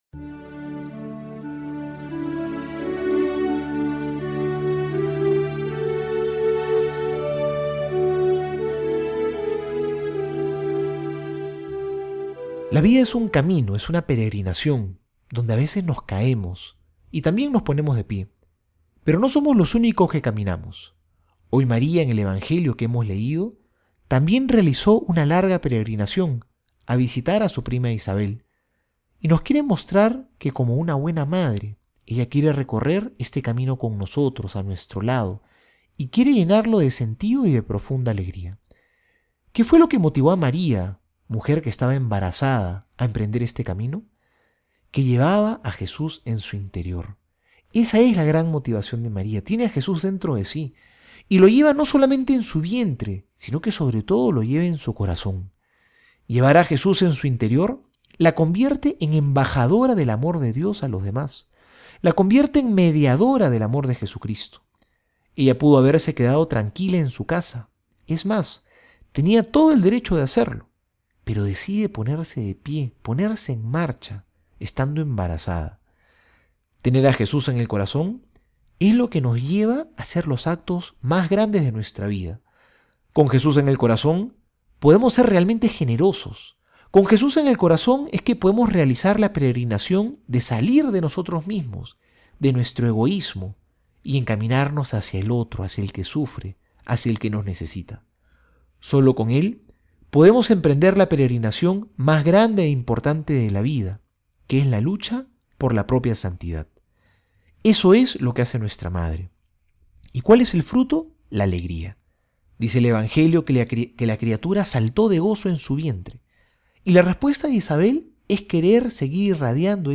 Homilía para hoy:
miercoles homilia.mp3